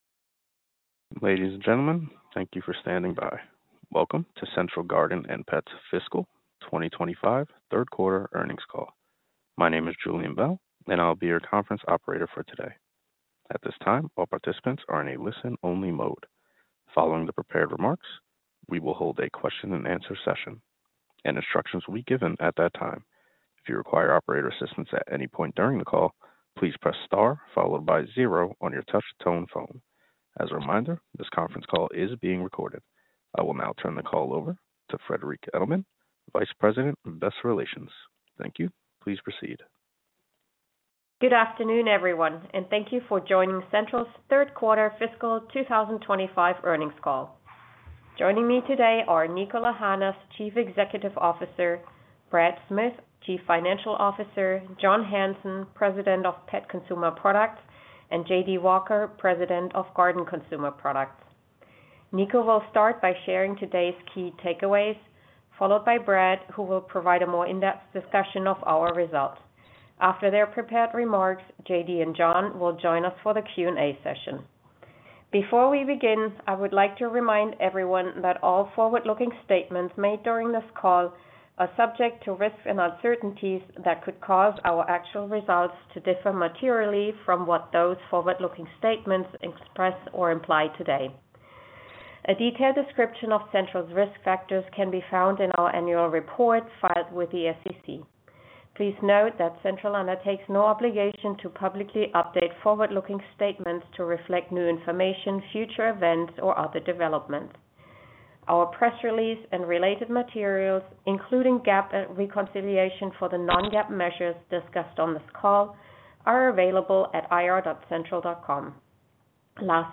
Q3 Fiscal 2025 Earnings Conference Call